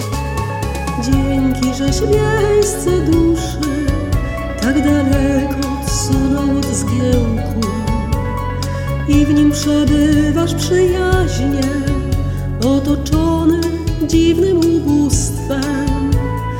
pieśniarka, instruktorka muzyki